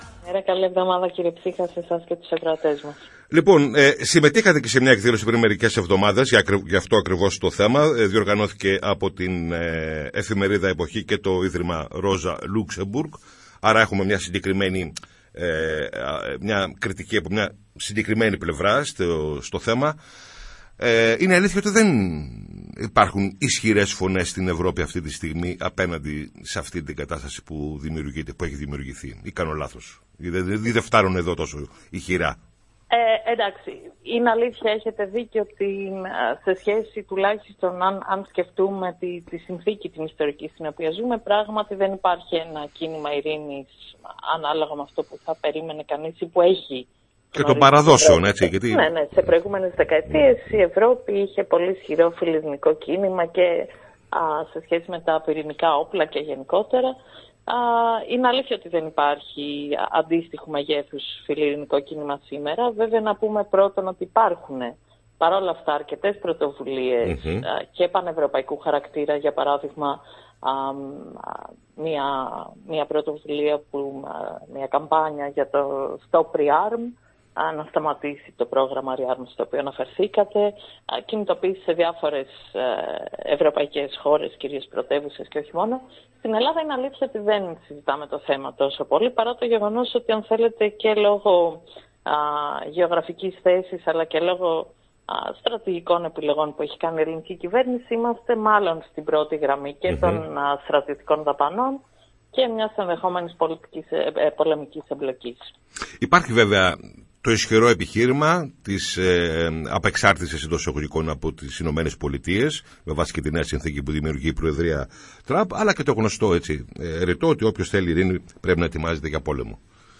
ήταν καλεσμένη στην ραδιοφωνική εκπομπή «Πολιτικό Ημερολόγιο» της ΕΡΤ Λάρισας